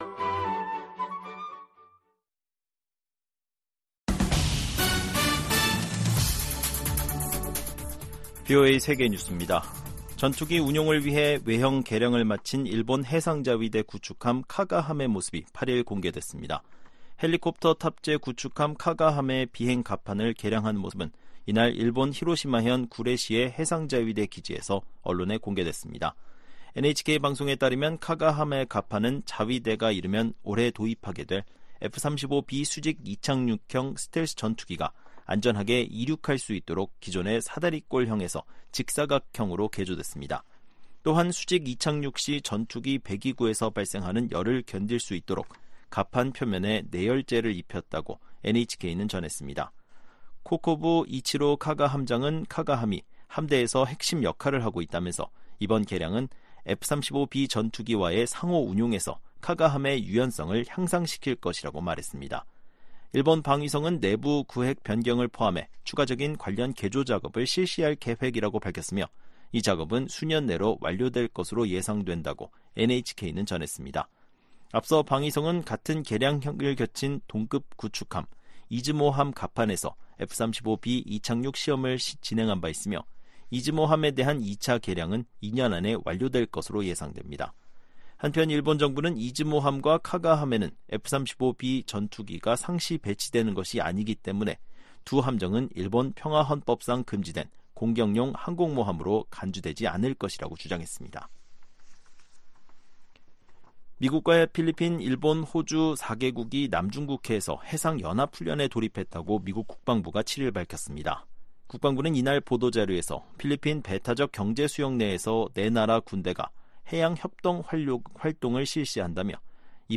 VOA 한국어 아침 뉴스 프로그램 '워싱턴 뉴스 광장' 2024년 4월 9일 방송입니다. 전천후 영상 정보 수집이 가능한 한국의 군사정찰위성 2호기가 8일 발사돼 궤도에 안착했습니다. 북한 김일성 주석을 ‘가짜’로 판단하는 1950년대 미국 정부 기밀 문건이 공개됐습니다. 미 국무부는 러시아가 한국의 대러 독자제재에 반발,주러 한국대사를 불러 항의한 것과 관련해 한국의 제재 조치를 환영한다는 입장을 밝혔습니다.